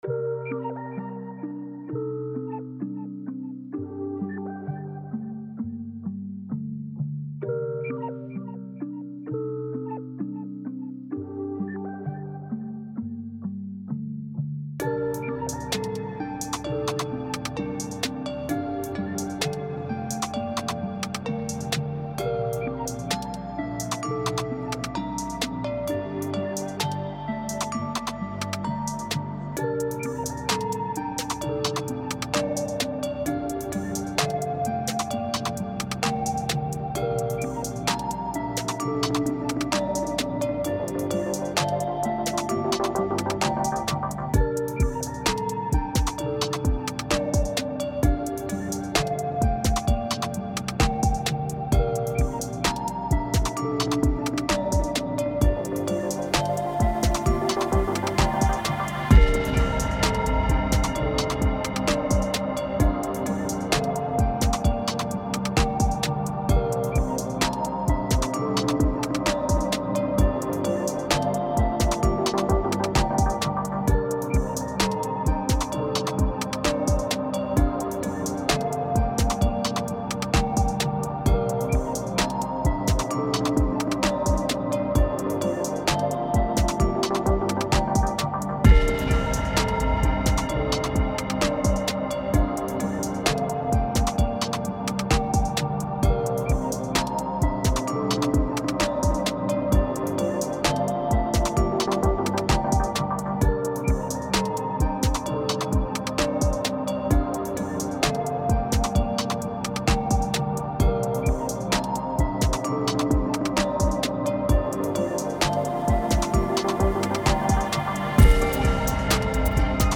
• Version Remix [Instrumental Version]
This is the instrumental version.
Tempo 130BPM (Allegro)
Genre Dark Trap Chill
Type Instrumental
Mood Conflicting (Sorrow/Chill)